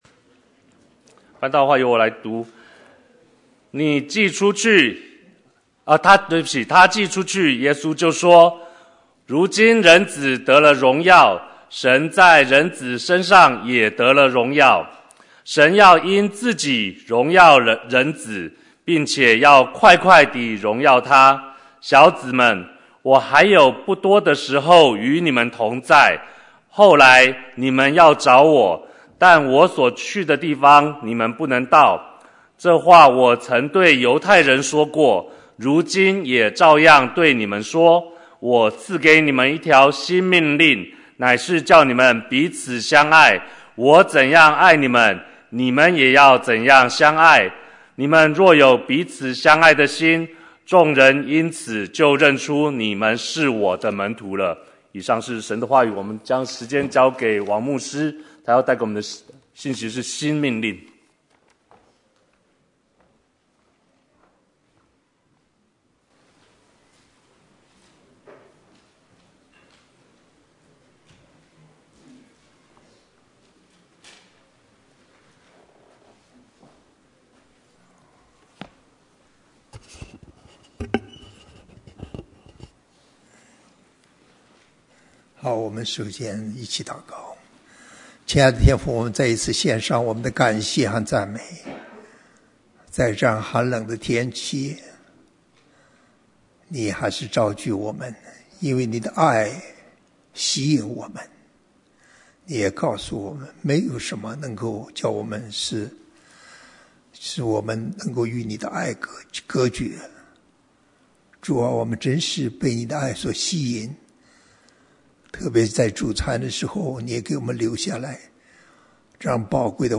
新生命 • 明州雙福基督教會